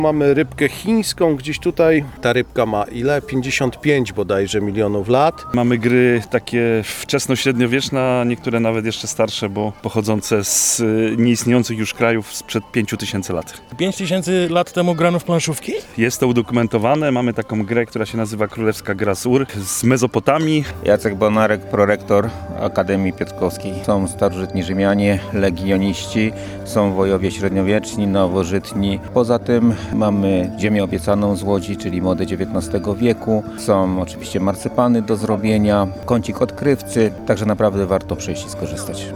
W Piotrkowie Trybunalskim odbył się X Piknik Historyczny – „Wioska Wikingów”
– Mamy grę planszową z Mezopotamii, która nazywa się królewska gra z Ur, która była prekursorem tryktraka – mówi jeden z uczestników “Wioski Winkingów”.